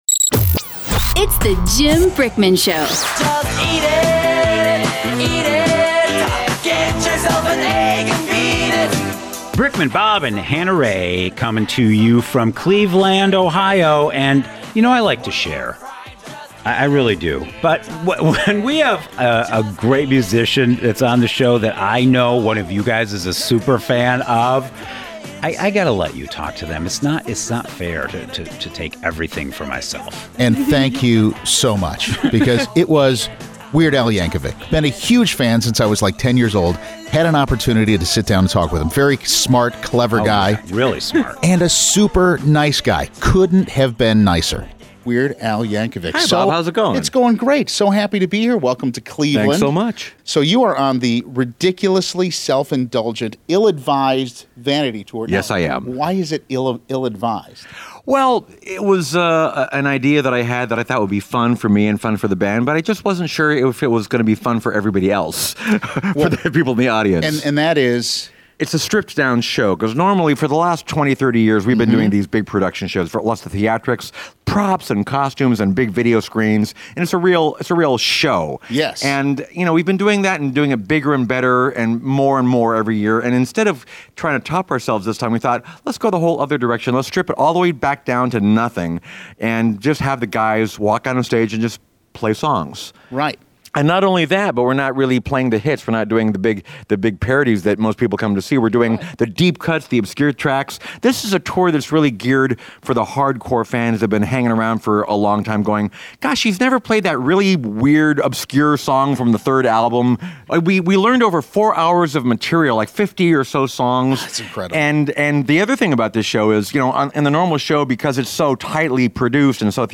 part one of our interview with Weird Al here!